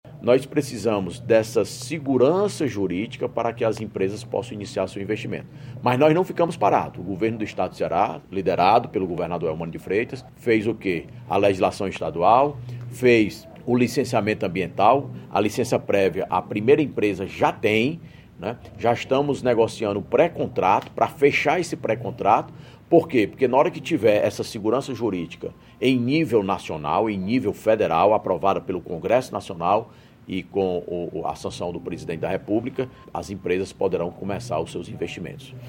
Em entrevista, Salmito Filho discutiu não apenas os avanços presentes, mas também os desafios futuros. Ele ressaltou o objetivo para 2024 de manter os investimentos públicos e atrair capital privado adicional.